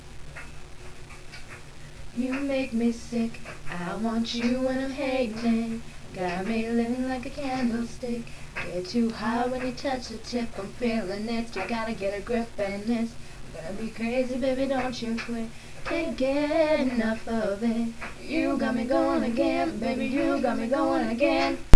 Please Note...they Do NOT Play Instruments